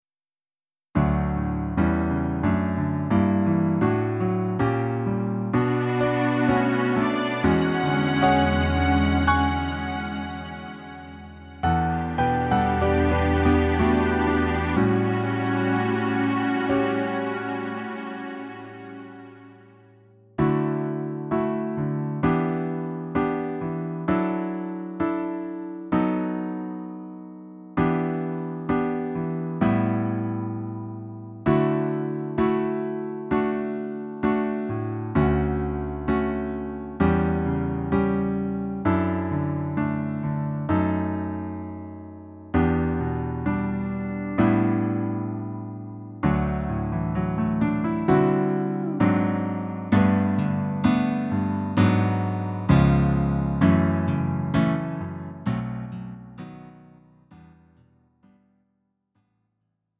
음정 원키 4:27
장르 가요 구분 Lite MR